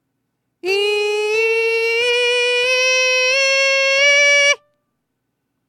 『イ』母音で強いミックスボイス？の参考音源
音量注意！
パターン１は『イ』をなるべくそのまま維持して発声しようとした音源で、パターン２は高くなるにしたがって『エ』を意識して発声した音源です。
でも実際にはパターン1も最後のD5は『イ』を維持できずに『エ』っぽくなってますね・・・（ ;◉◞౪◟◉)＞ﾃﾍﾍ